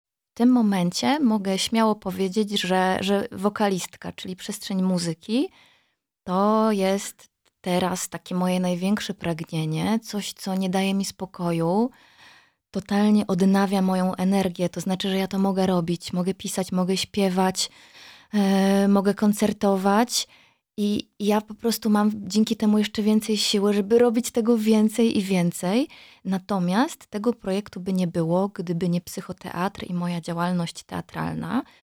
W rozmowie w studiu Radio Rodzina aktorka opowiada o genezie projektu muzycznego, sile teatru oraz autorskiej metodzie pracy z ciałem i emocjami.